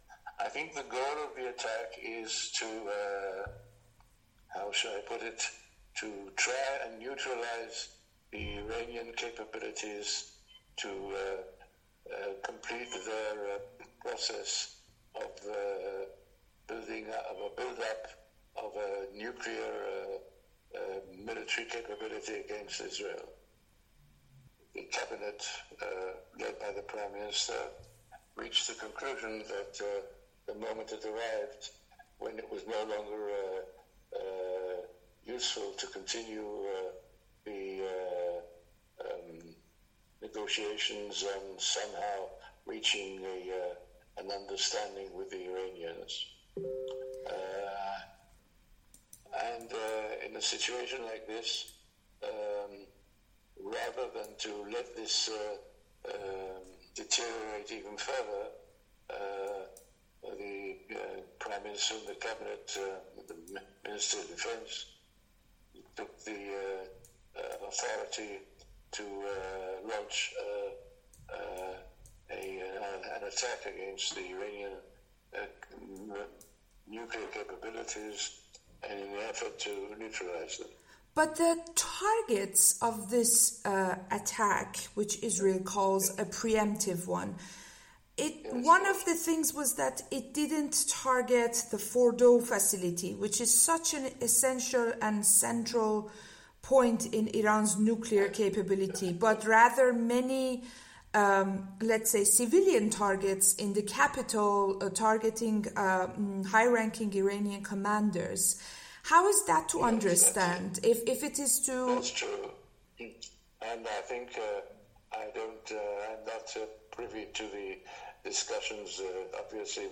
گفت‌وگو با افرائیم هالوی پیرامون حمله اسرائیل به ایران